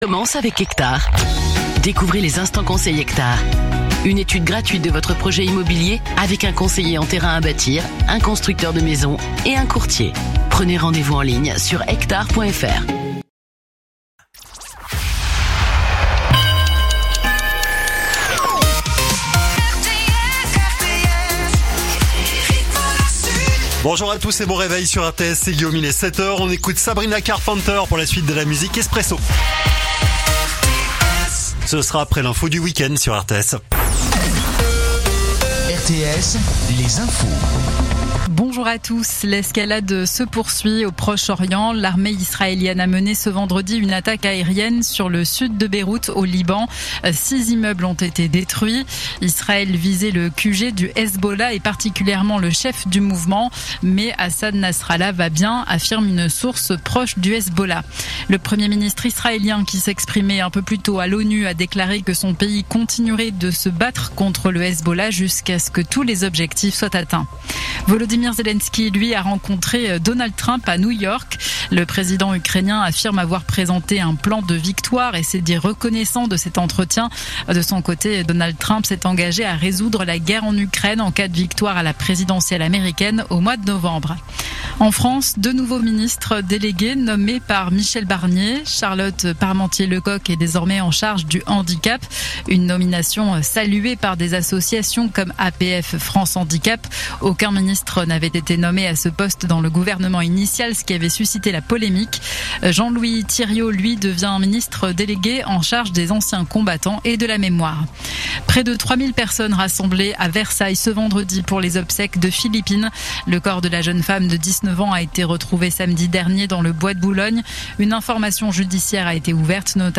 Écoutez les dernières actus de Nîmes en 3 min : faits divers, économie, politique, sport, météo. 7h,7h30,8h,8h30,9h,17h,18h,19h.
info_nimes_155.mp3